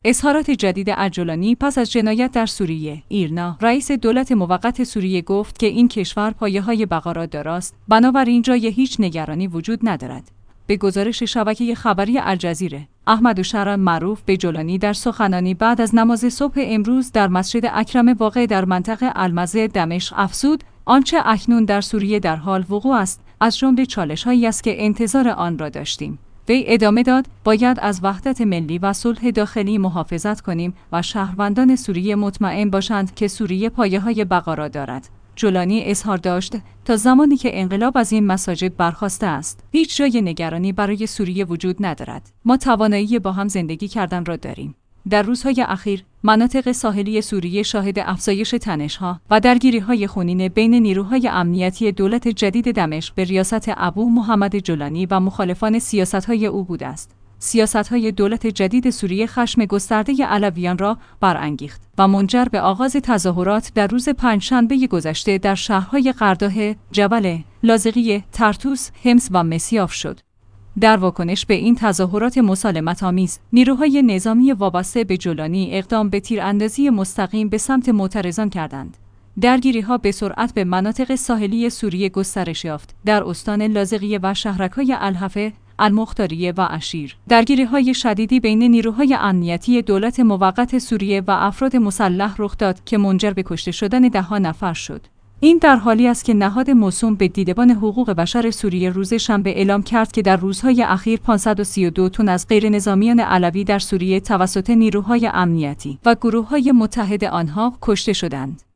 به گزارش شبکه خبری الجزیره، «احمد الشرع» معروف به جولانی در سخنانی بعد از نماز صبح امروز در مسجد اکرم واقع در منطقه «المزه» دمشق افزود: آنچه اکنون در سوریه درحال وقوع است، از جمله چالش هایی است